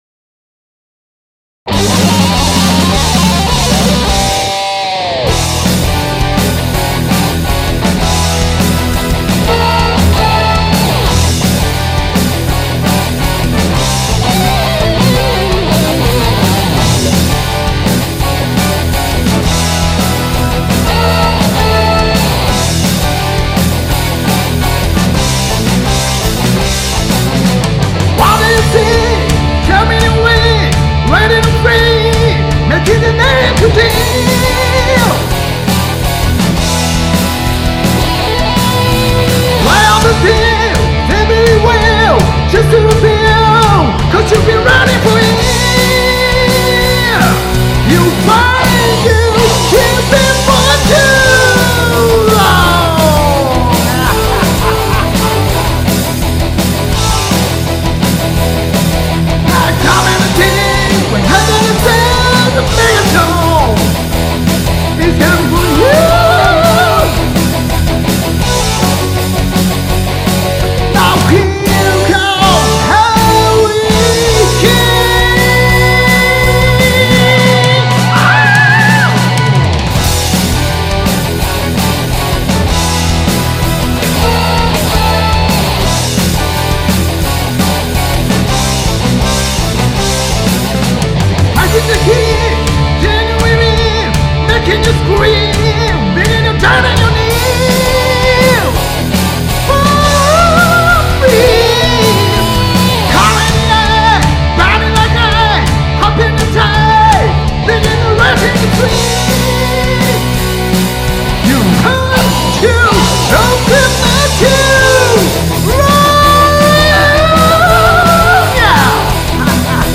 ドラムとキーボードやってます。
ベンドレバーをじゃんじゃん使いたかっただけ